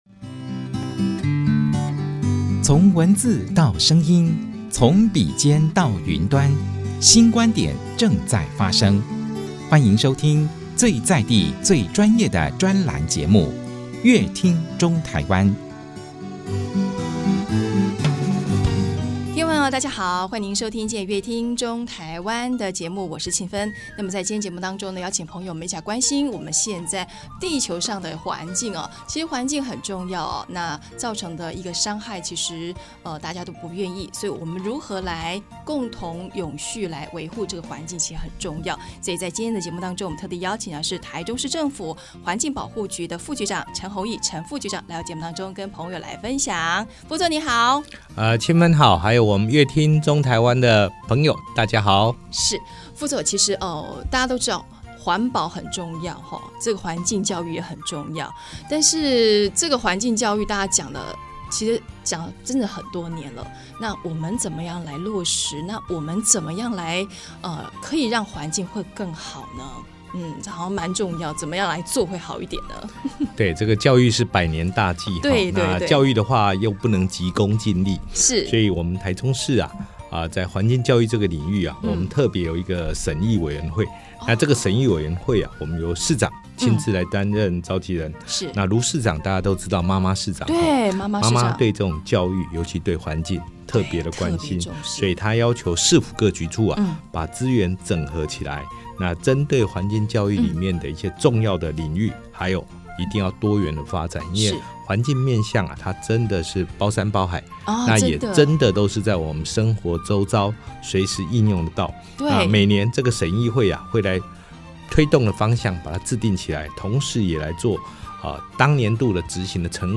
本集來賓：台中市政府環保局陳宏益副局長 本集主題：「親身體驗做起 落實環境教育」 本集內容： 看到海龜的鼻子插